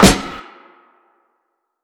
LiveSnr_clap.wav